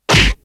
0003_烟灰缸砸头.ogg